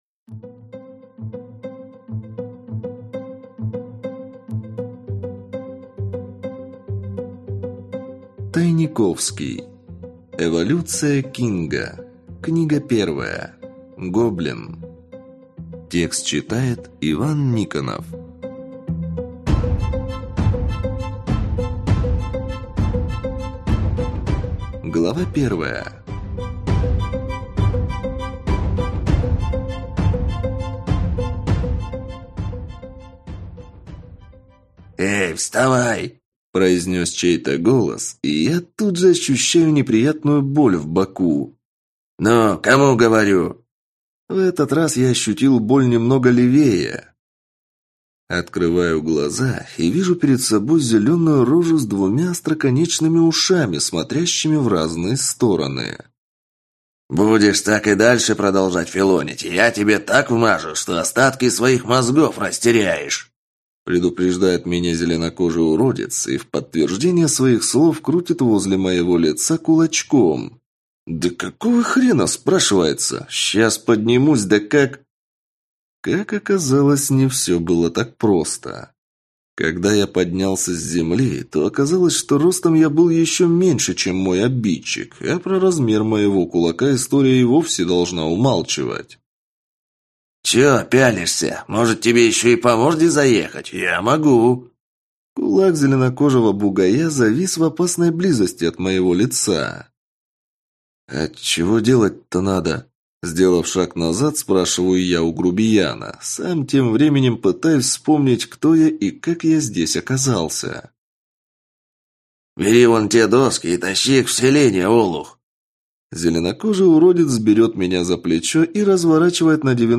Aудиокнига Гоблин